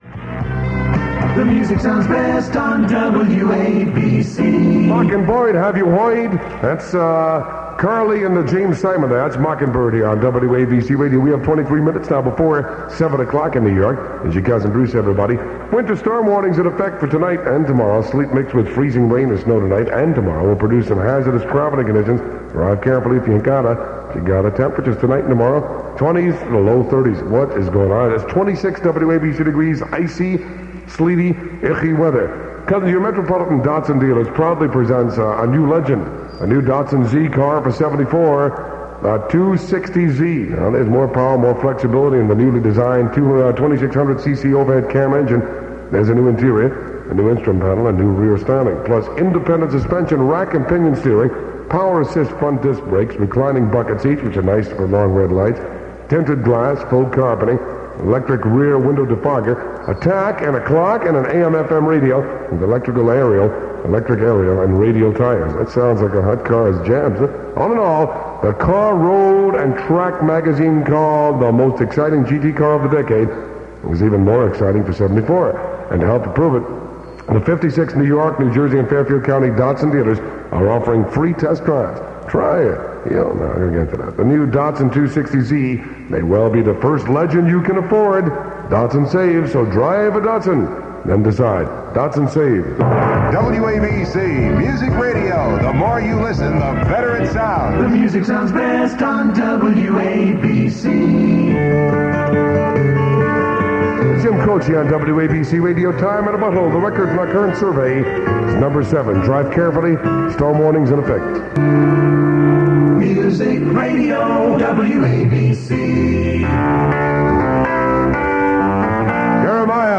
Aircheck of the Week
Enjoy Cousin Brucie on WABC (SCOPED)